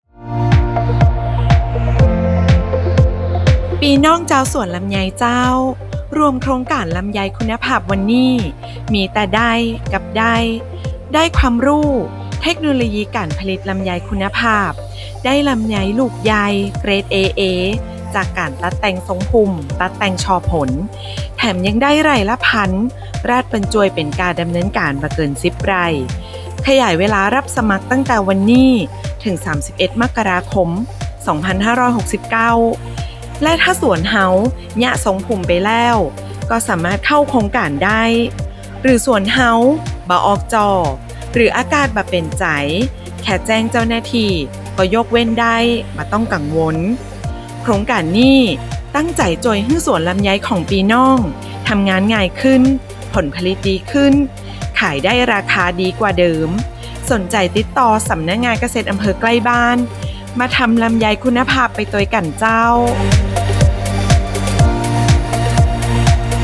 SPOT RADIO